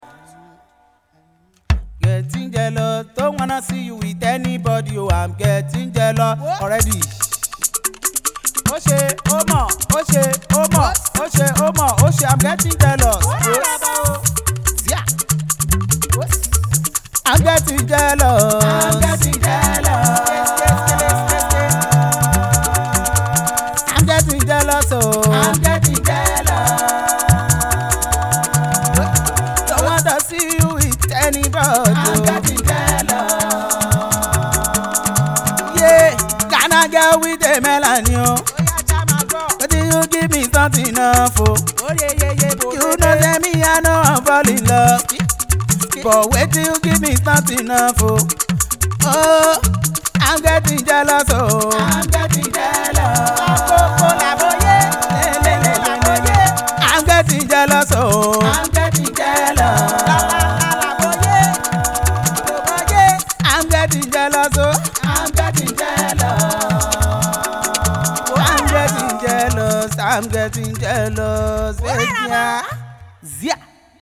Fuji fusion cover